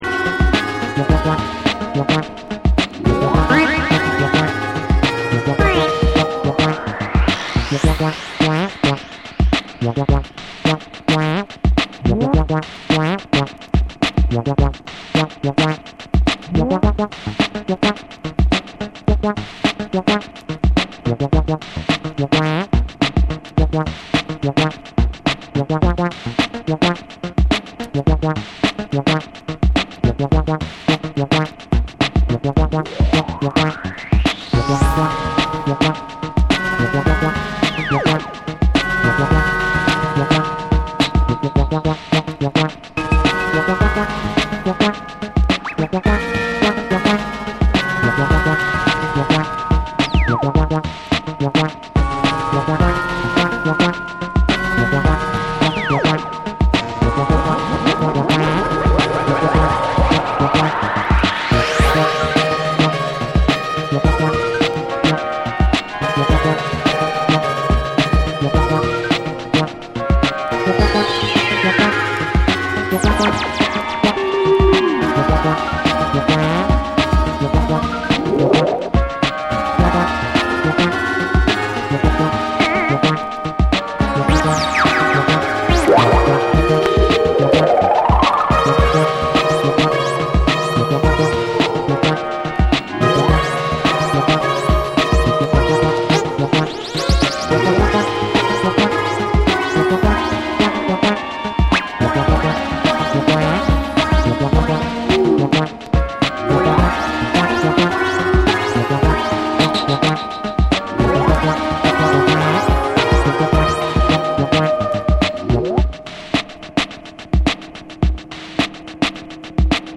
サントラ/ライブラリー好きから、エレクトロニカ／ダウンテンポのリスナーまで幅広くおすすめ。
BREAKBEATS